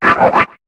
Cri de Chamallot dans Pokémon HOME.